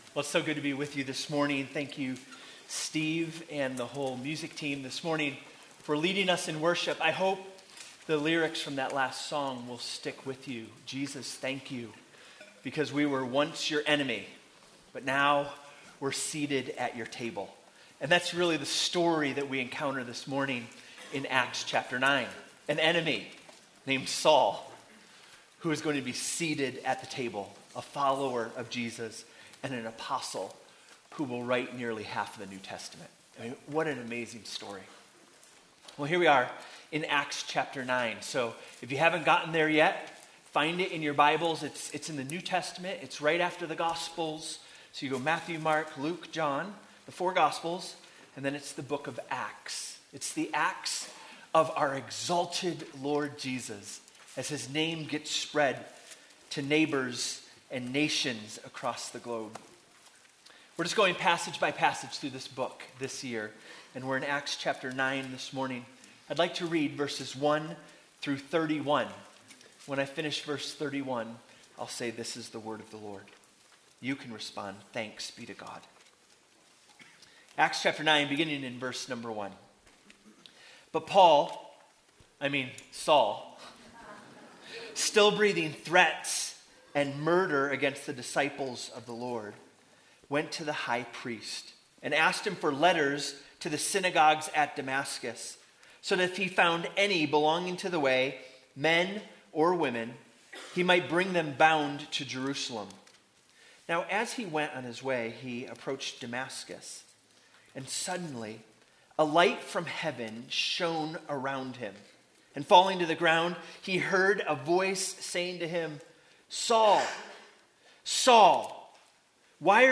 Gospel Grace Church Sermon Audio